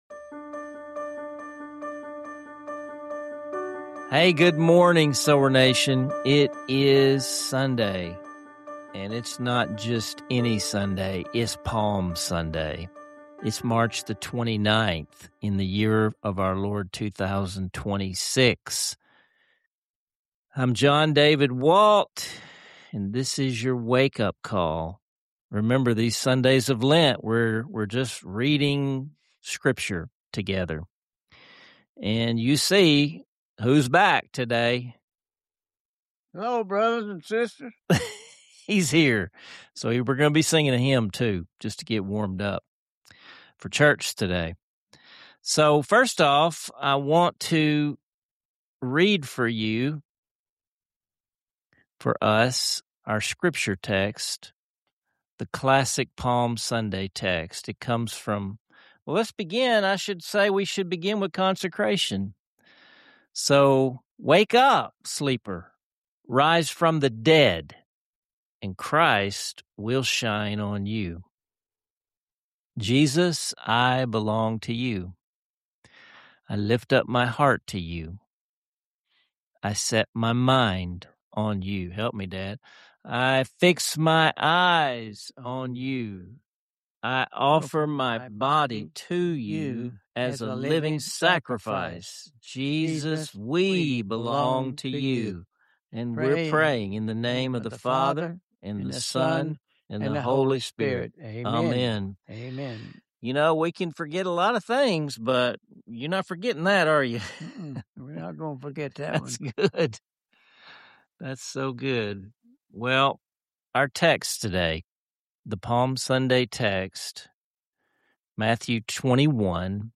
Whether you’re preparing for church or looking to enrich your understanding of Holy Week, this episode offers encouragement, hope, and practical wisdom to carry with you all week long. Don’t miss out on these heartening conversations and beautiful hymns—subscribe to Seedbed Inc for more inspirational moments and thoughtful spiritual reflections!